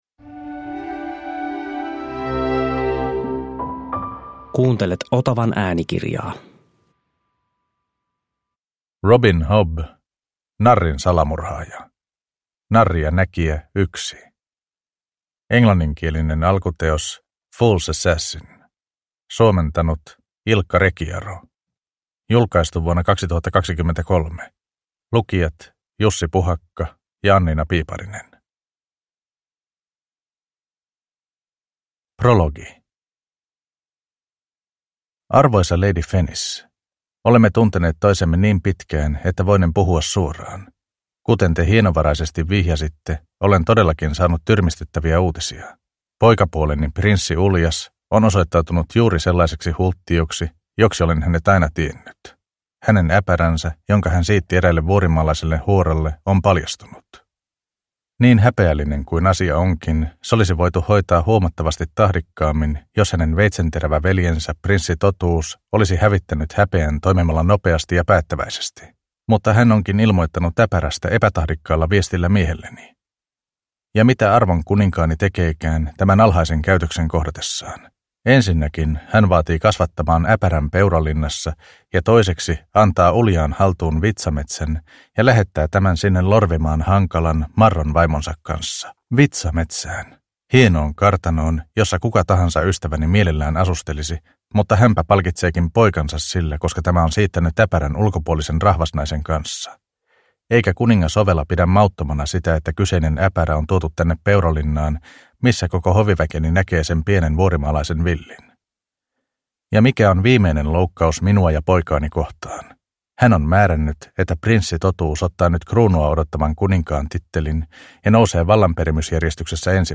Narrin salamurhaaja – Ljudbok – Laddas ner